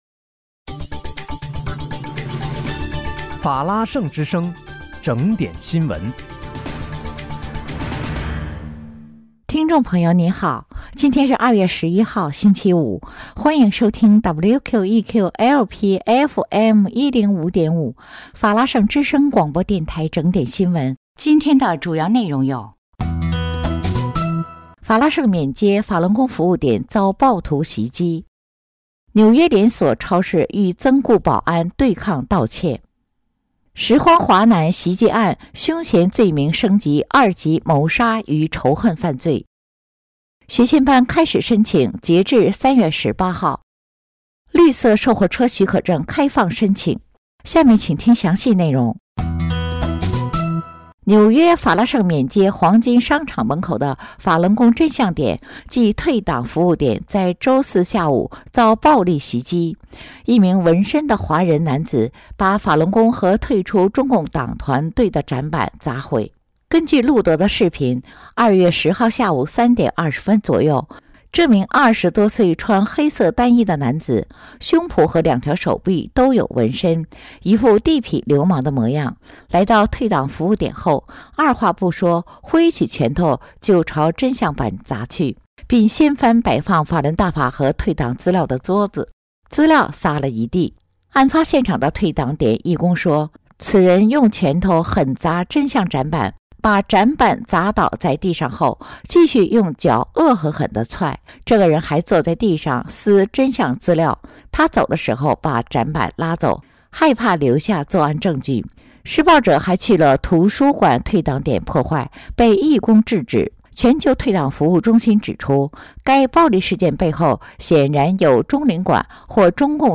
2月11日（星期五）纽约整点新闻